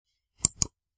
Download Mouse Click sound effect for free.
Mouse Click